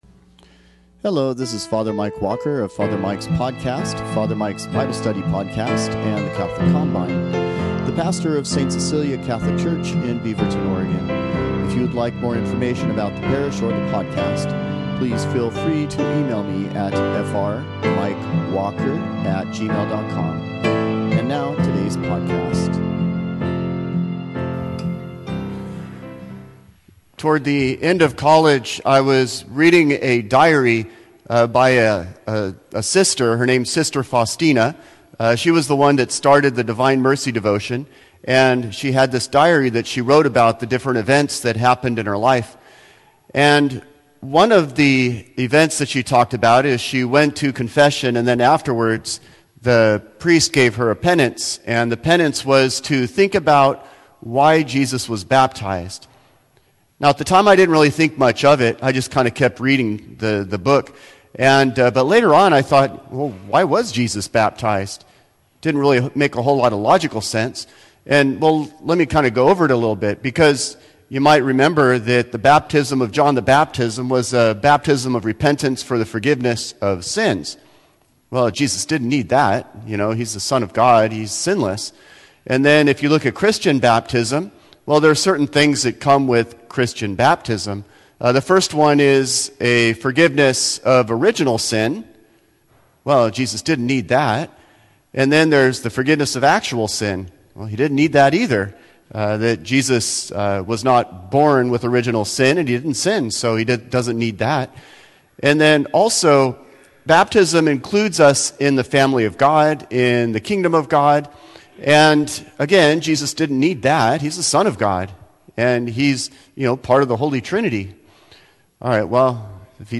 Religion Catholic Church Preaching Priest Bible Faith God Jesus Mass Reflections Prayer Christianity
Sunday homilies